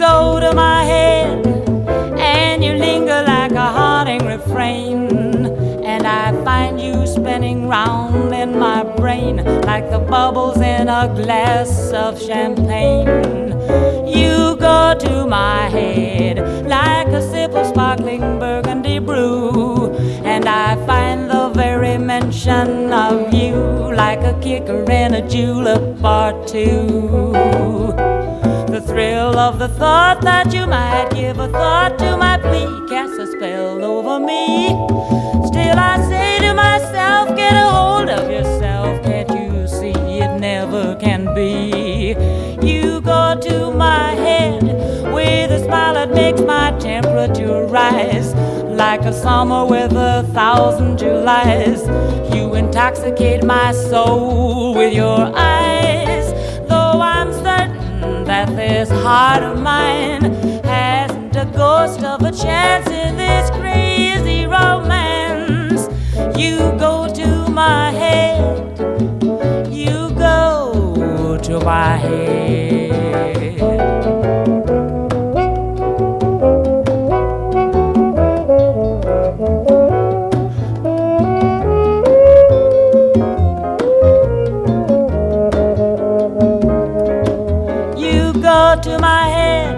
WORLD / LATIN / BOSSA NOVA
素晴らしいストリングス・アレンジに魅了されるボサノヴァ/ラテン・ヴォーカル大傑作！